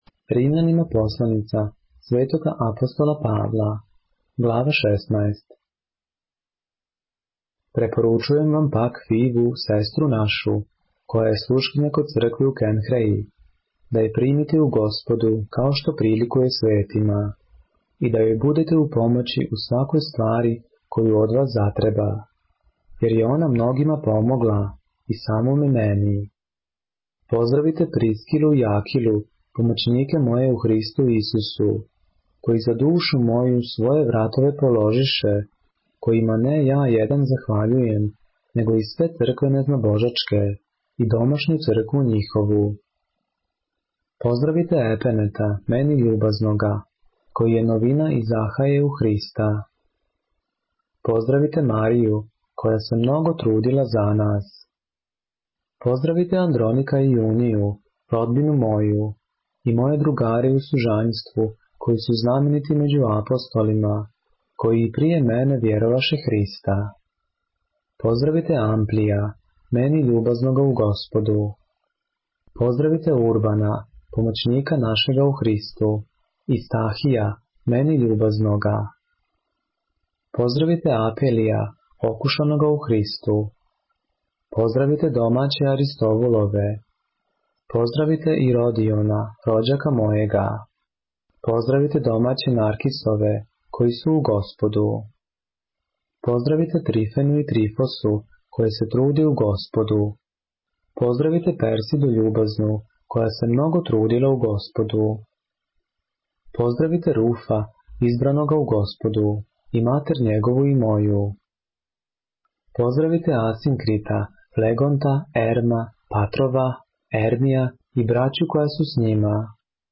поглавље српске Библије - са аудио нарације - Romans, chapter 16 of the Holy Bible in the Serbian language